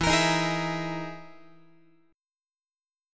Listen to EM#11 strummed